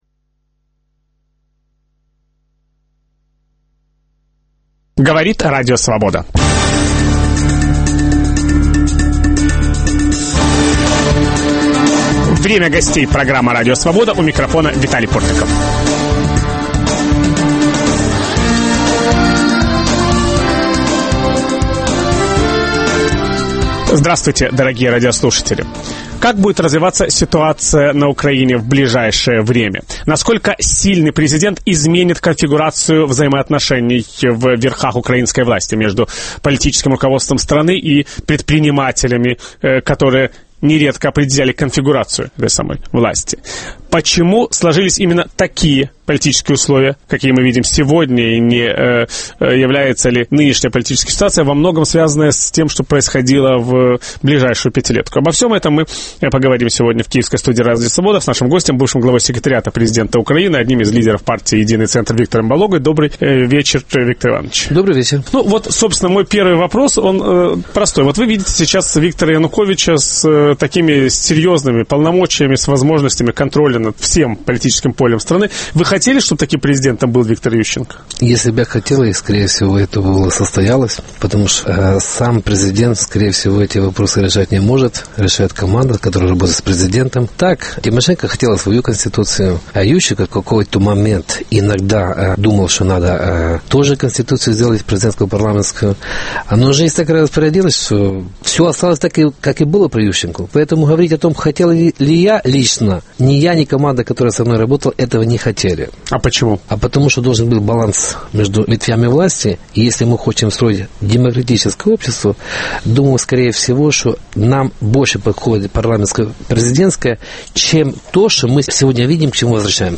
Будет ли на Украине сильная власть? В программе участвует бывший глава секретариата президента Украины Виктор Балога.